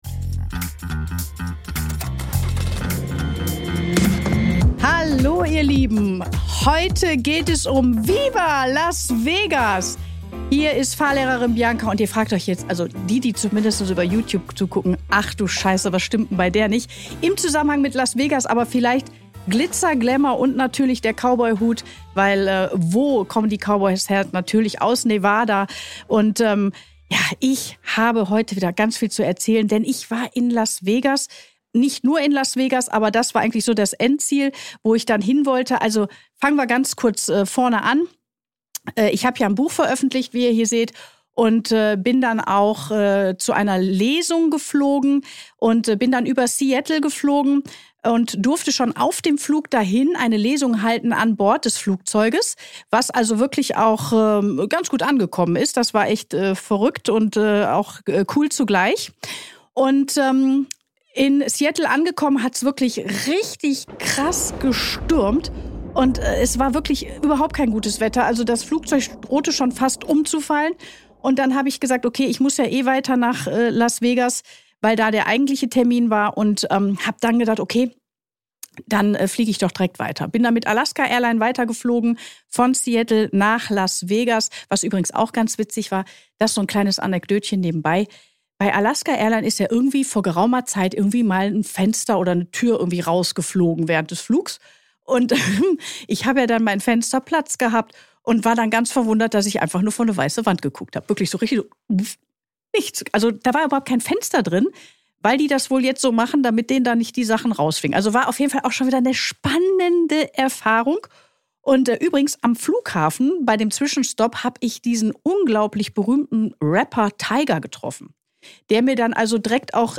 Im Gespräch mit Mindset-Coach